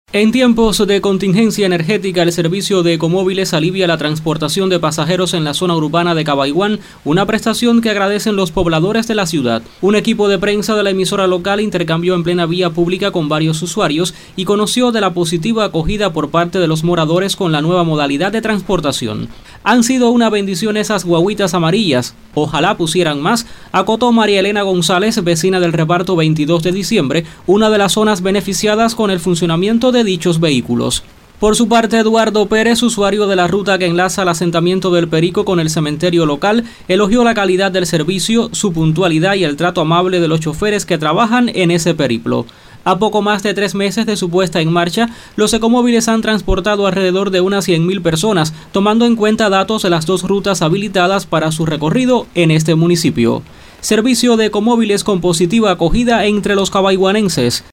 Así lo constató un equipo de prensa de esta emisora en plena vía pública.